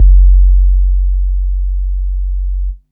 DC nord sub.wav